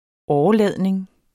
Udtale [ ˈɒːɒˌlæˀðniŋ ]